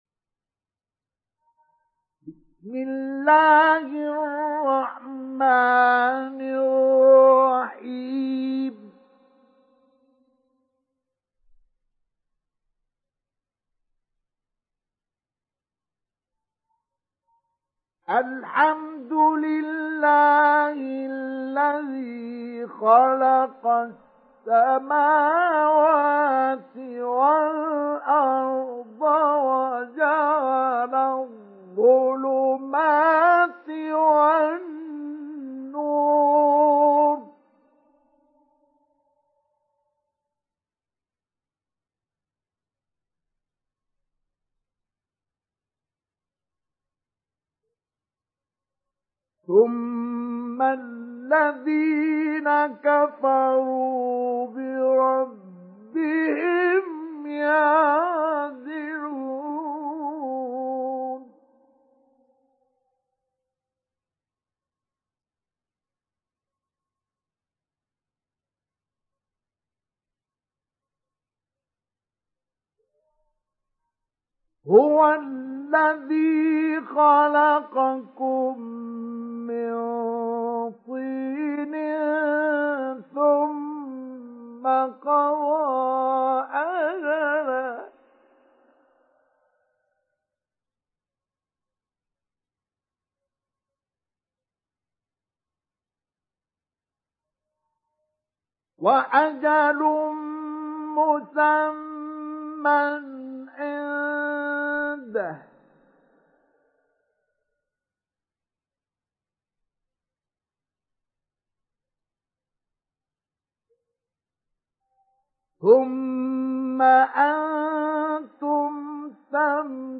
سُورَةُ الأَنۡعَامِ بصوت الشيخ مصطفى اسماعيل